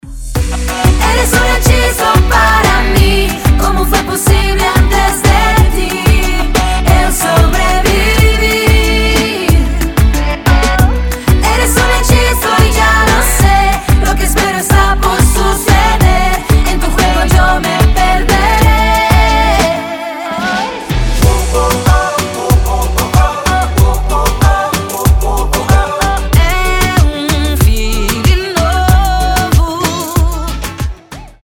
• Качество: 320, Stereo
громкие
красивые
женский вокал
зажигательные
заводные
Latin Pop